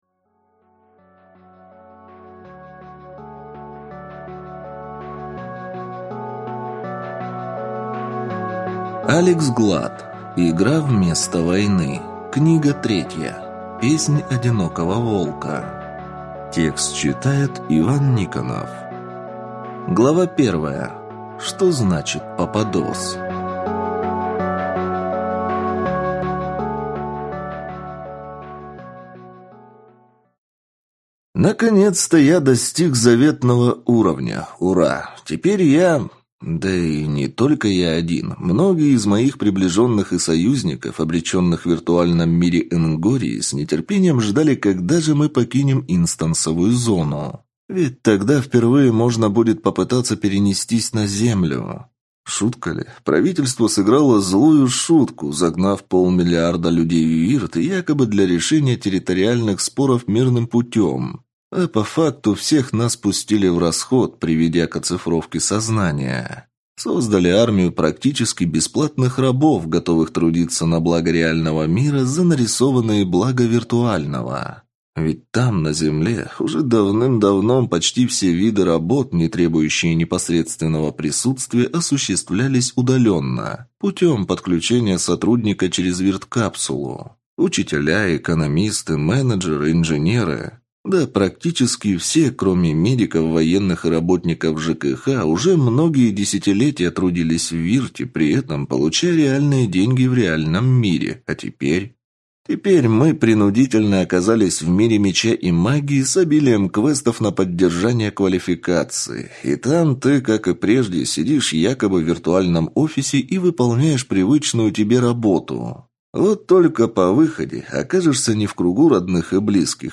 Аудиокнига Игра вместо войны. Песнь одинокого волка | Библиотека аудиокниг